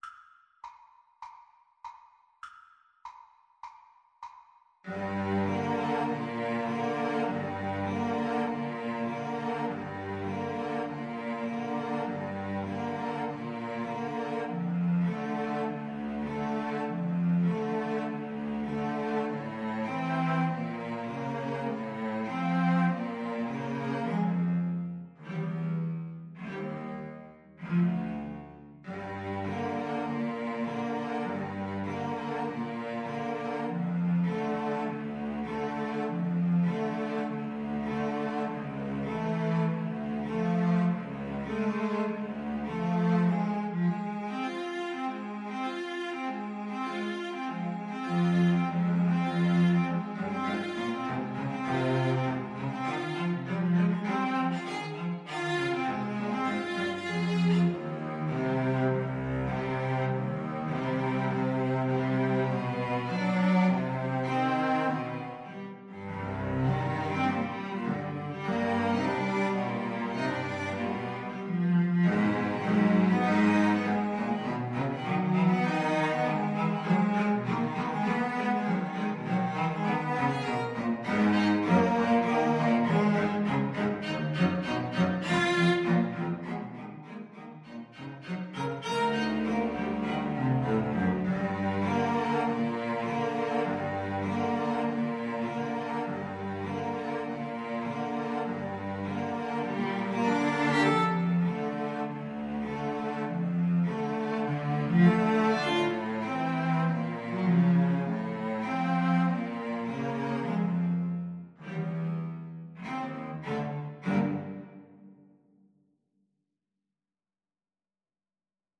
Cello Trio  (View more Advanced Cello Trio Music)
Classical (View more Classical Cello Trio Music)